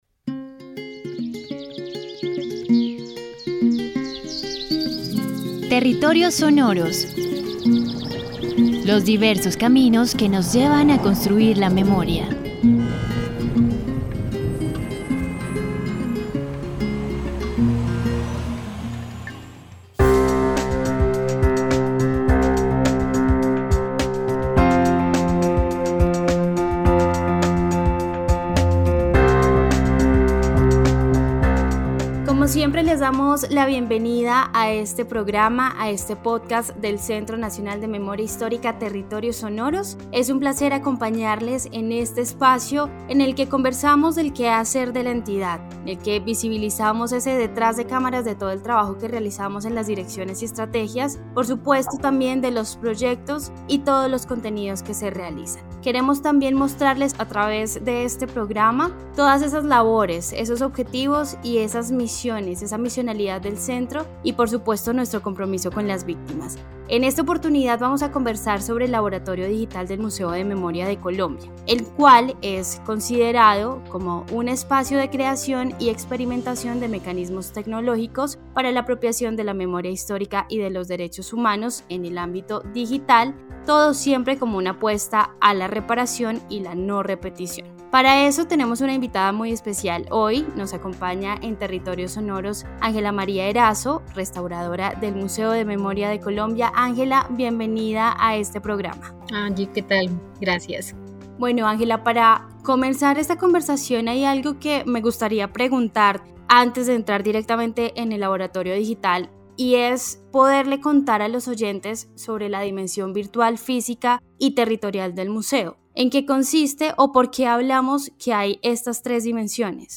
Charla sobre el Museo de Memoria de Colombia (MMC).